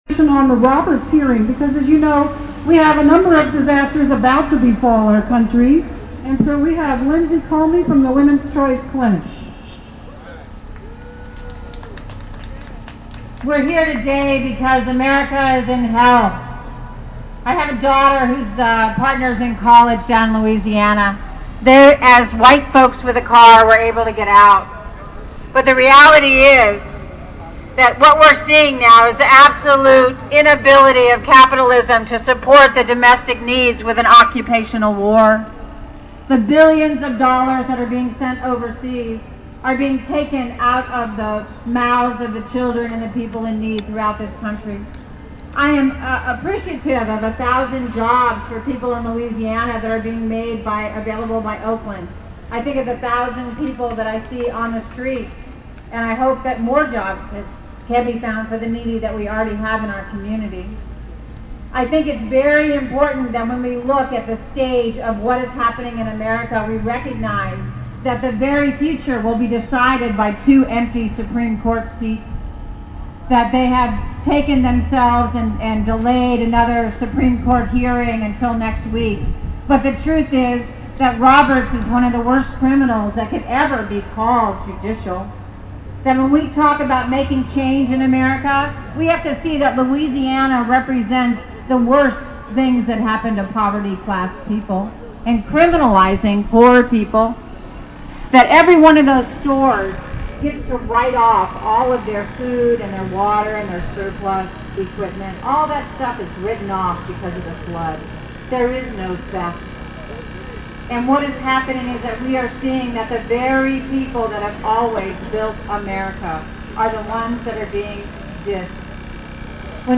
Bay Area Coalition for Our Reproductive Rights Protests Roberts Nomination (Photos&Audio)